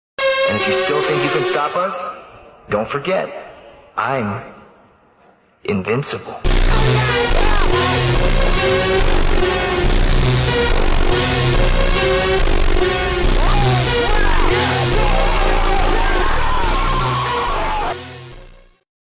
The dominator 3 team intercepted a ~200mph (320kph) and did a full 360 with windows down and not fully deployed they had around 5 seconds when they saw the unexpected subvortex spawned around 50 feet from them. Reed reported tons of debris flew on due to windows down and no time to roll up do to the surprise subvortex stretching out from the main tornado.